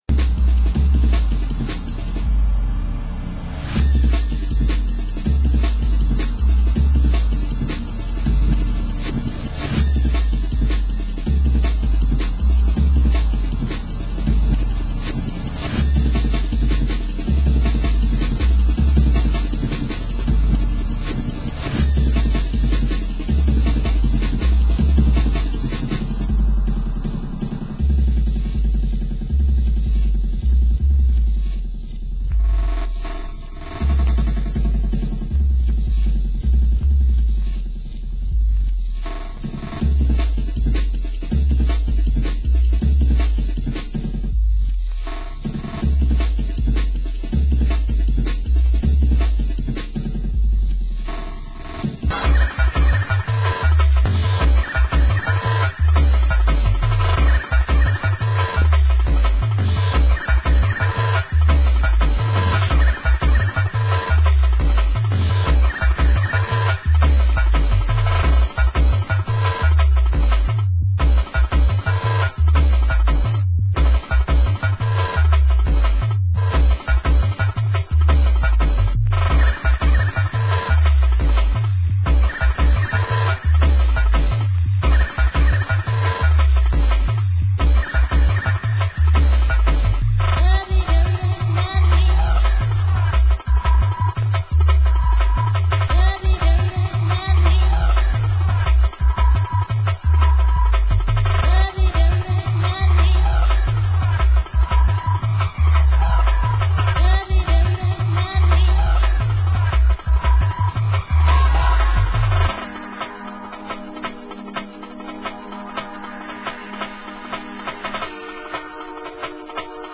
Format: Vinyl 12 Inch
Genre: Jungle